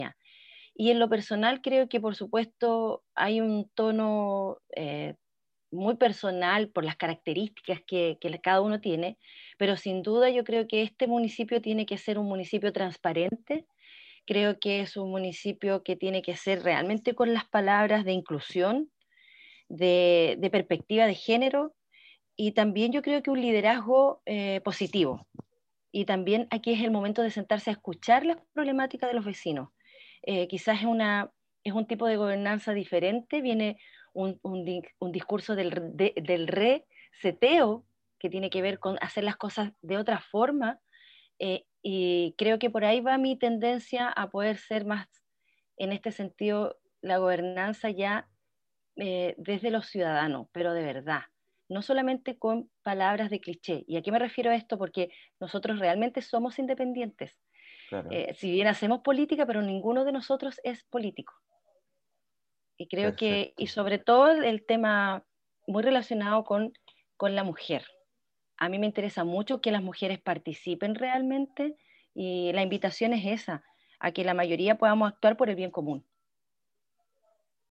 En conversación con Radio UdeC, la precandidata manifestó sentirse profundamente identificada con el proyecto de Municipio Ciudadano, ya que incluye ejes y valores importantes que comparte, como la democracia e integración de los barrios o el cuidado del medio ambiente.